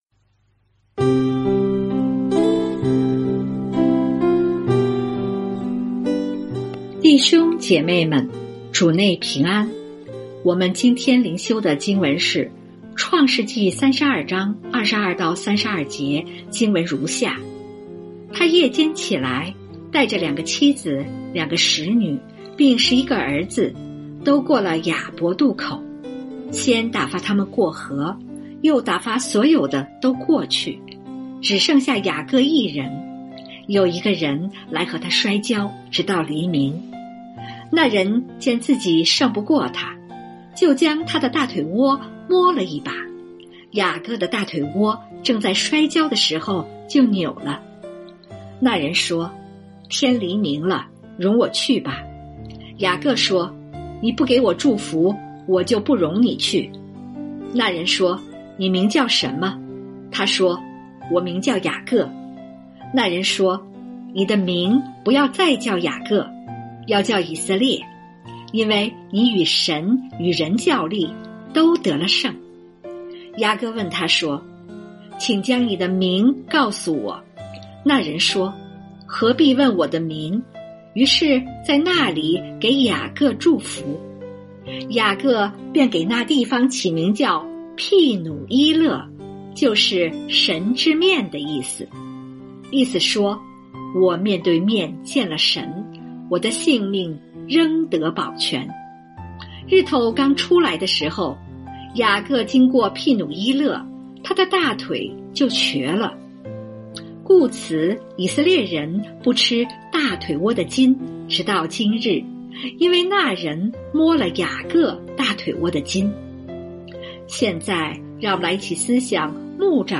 每天閱讀一段經文，聆聽牧者的靈修分享，您自己也思考和默想，神藉著今天的經文對我說什麼，並且用禱告來回應當天的經文和信息。